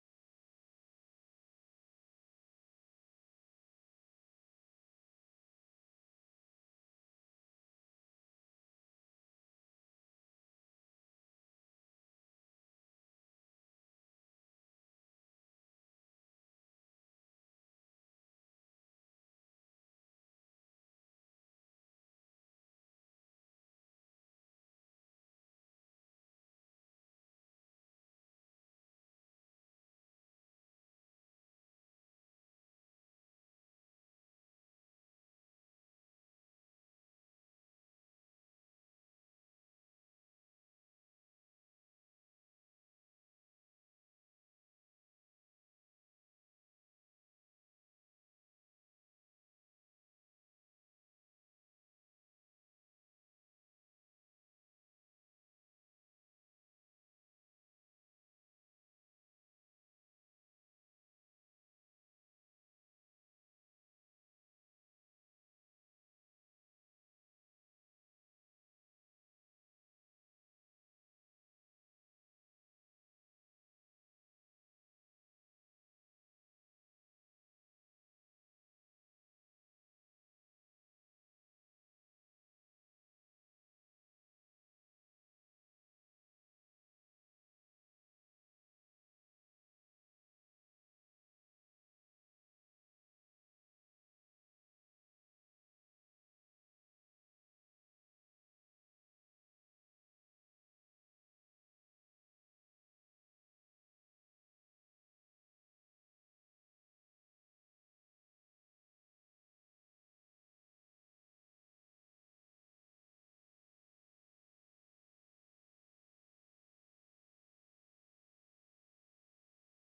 Cours de Pensée Juive sur la Paracha Hayé Sarah